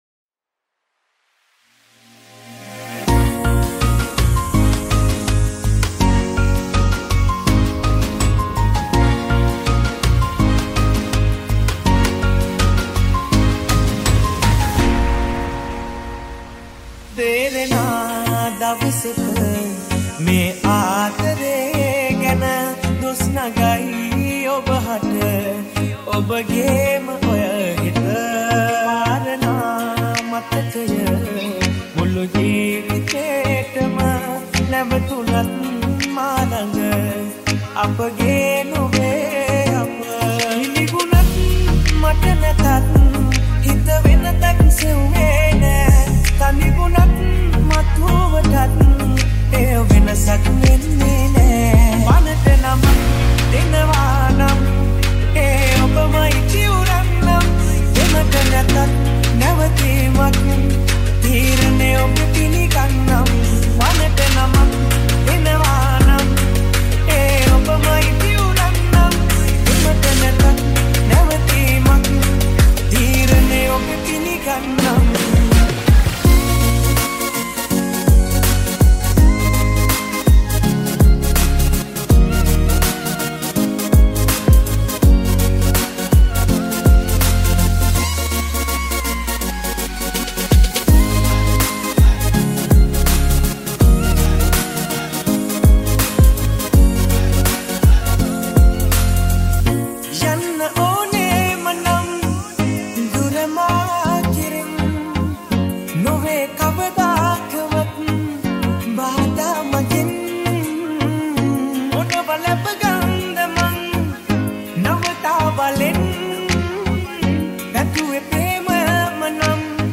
Sinhala Remix